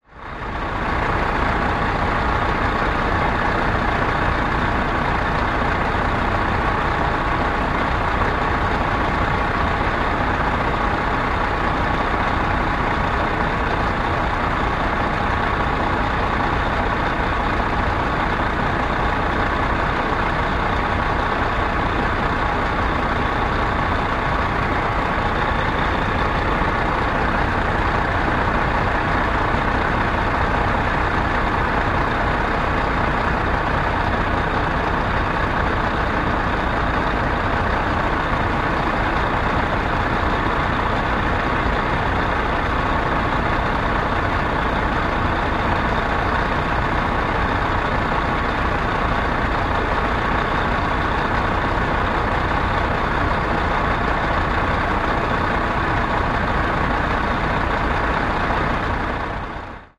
tr_dieseltruck_idle_03_hpx
Diesel truck idles. Vehicles, Truck Idle, Truck Engine, Motor